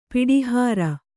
♪ piḍihāra